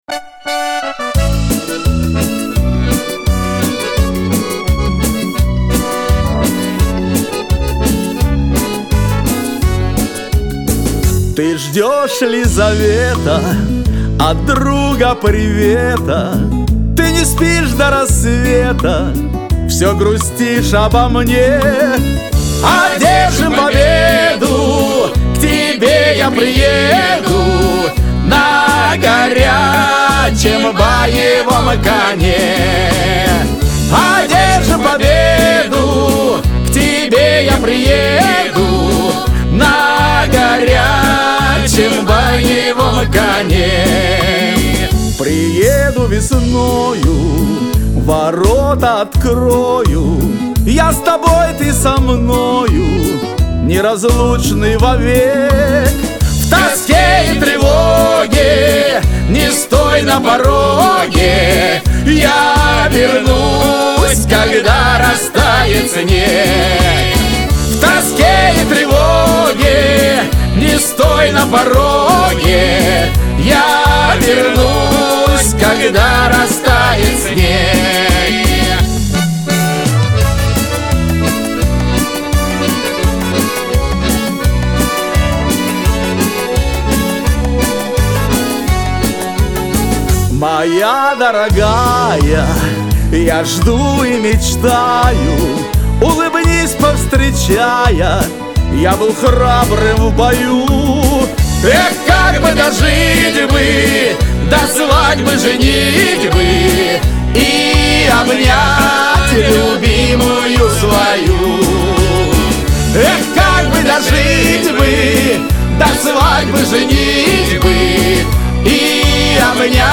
бэк-вокал